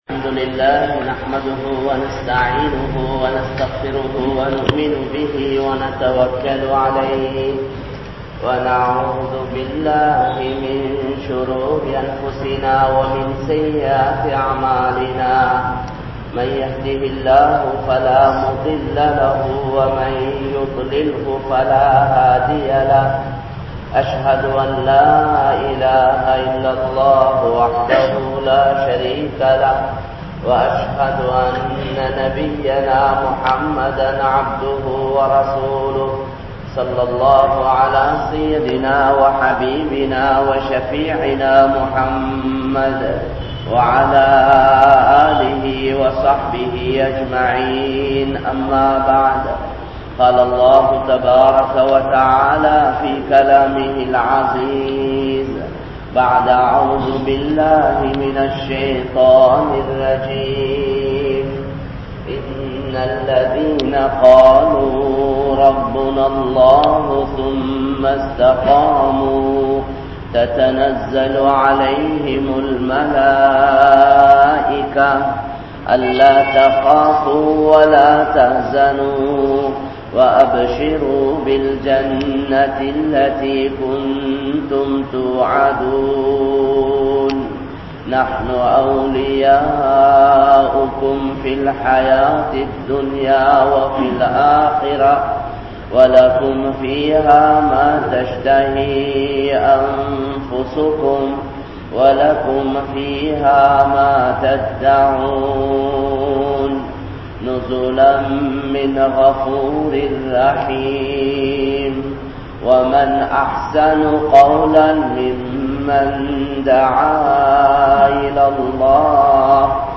Intha Ummaththin Kavalai (இந்த உம்மத்தின் கவலை) | Audio Bayans | All Ceylon Muslim Youth Community | Addalaichenai